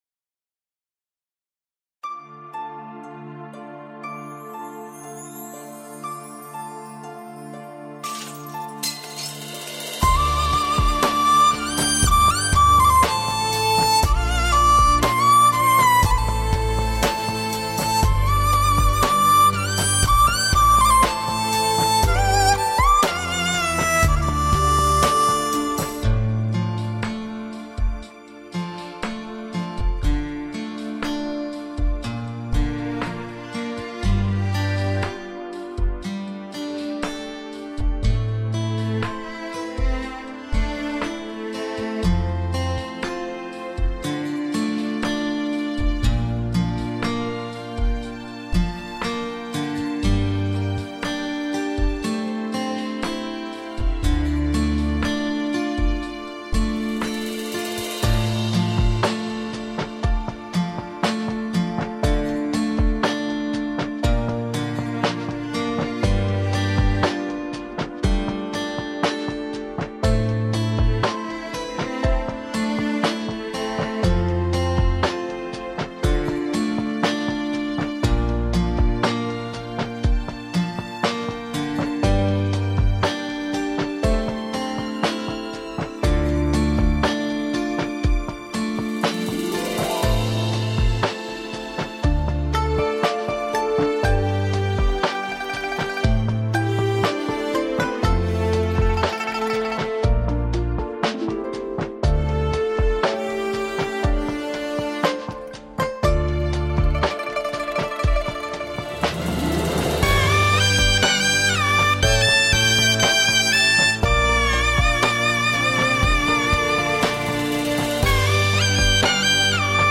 无 调式 : G 曲类